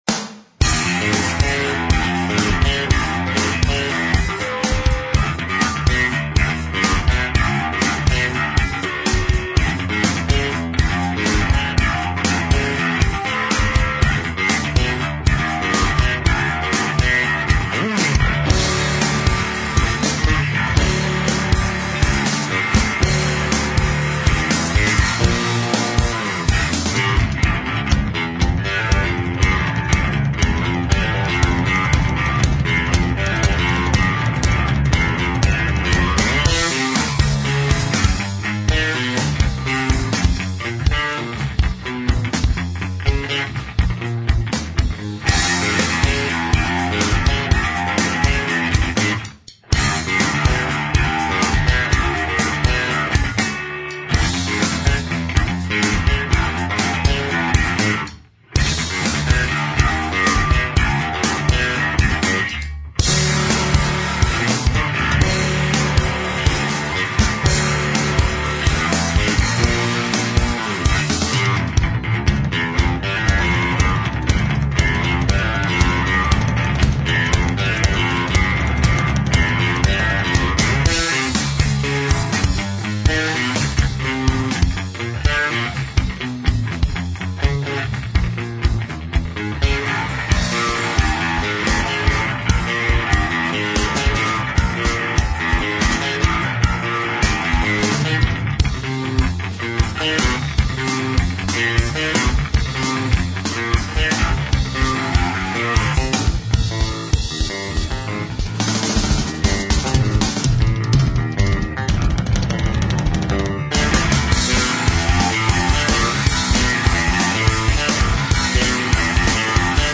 这个二人乐队